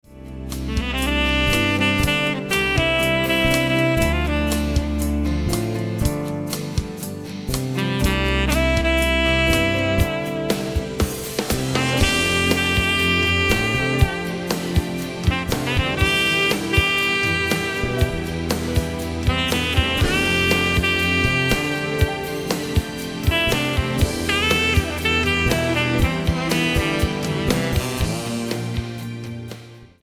140 BPM
Smooth jazz tenor track with
soft rock band and string section.